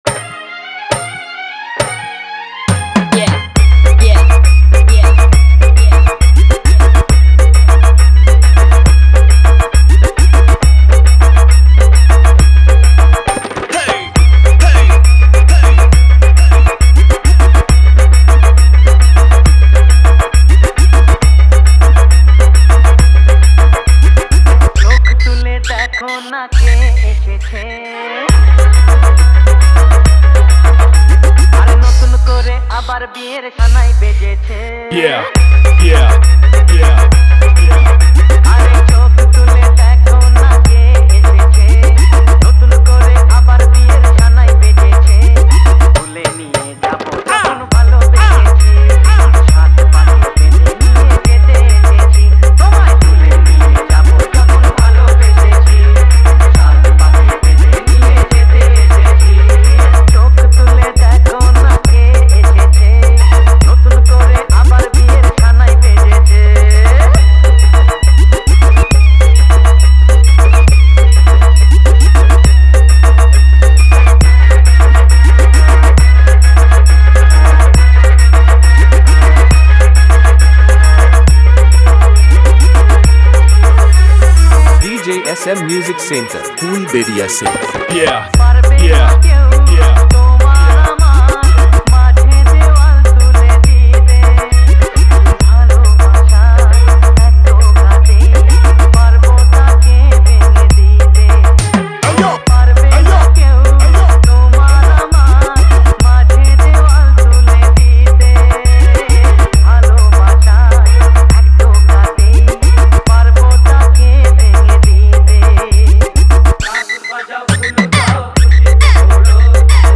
দুর্গা পুজো স্পেশাল বাংলা হামবিং মিক্স